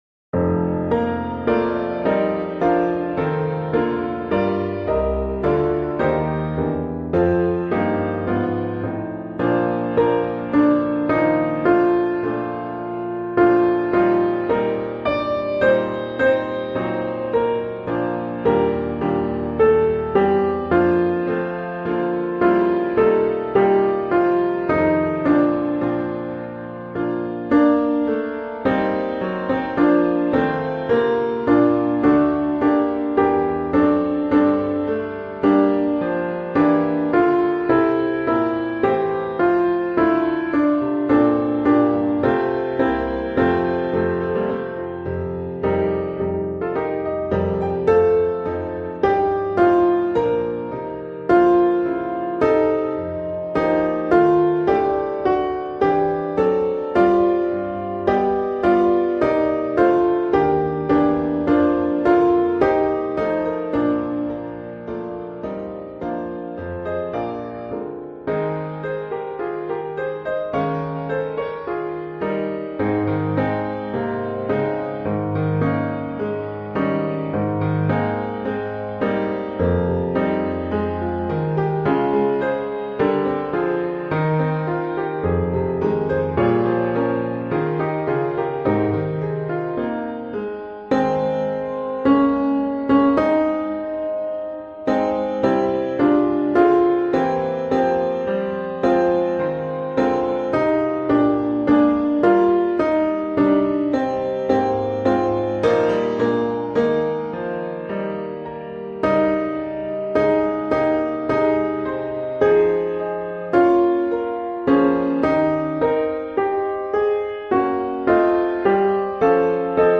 O Zion, Haste – Alto